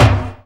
44_15_tom.wav